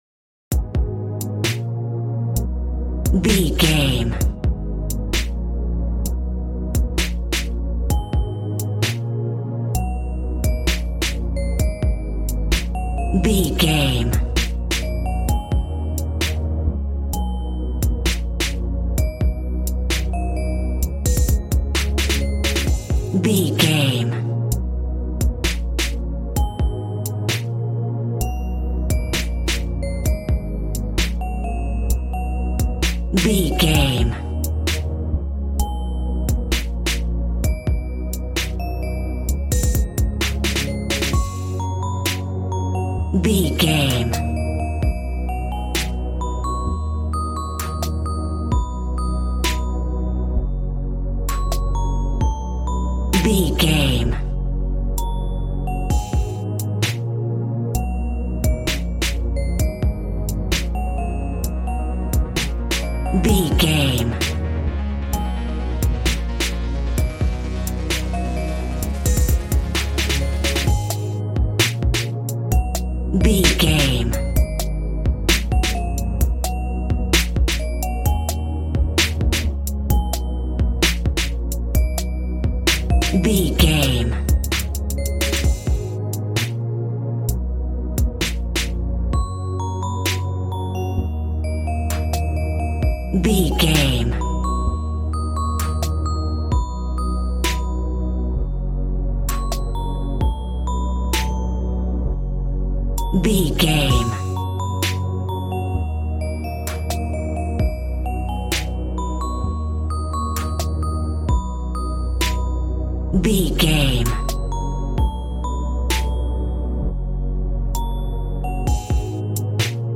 Ionian/Major
G♭
calm
smooth
synthesiser
piano